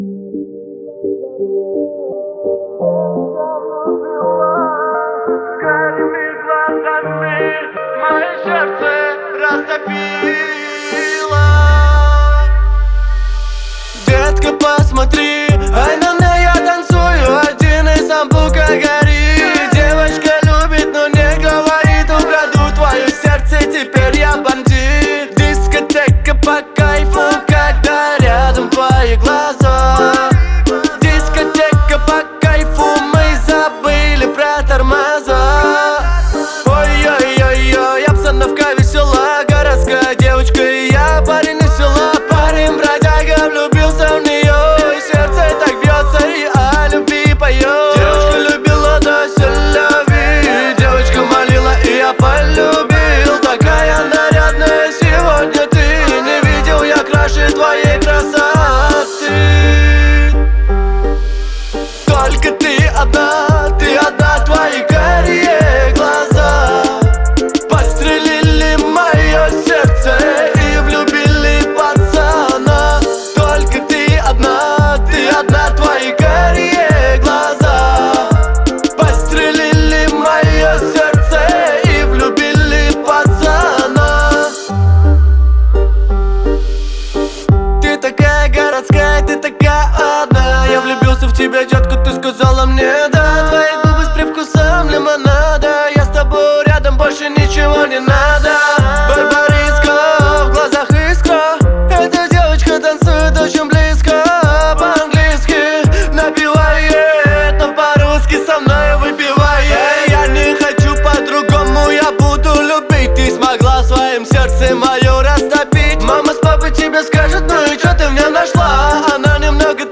романтичная и мелодичная песня